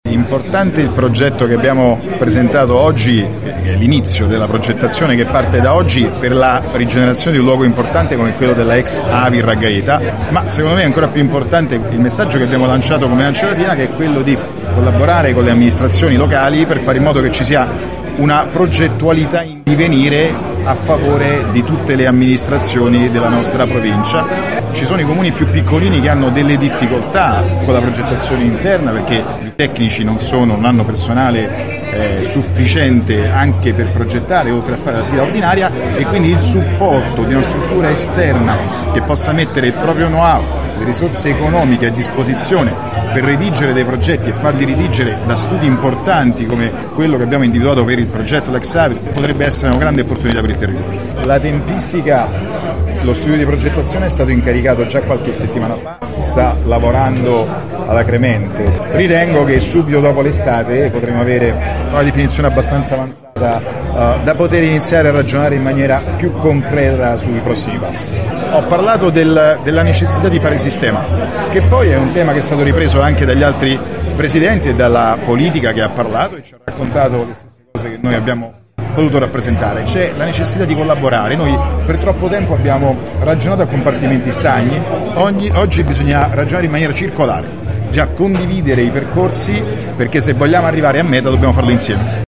Interviste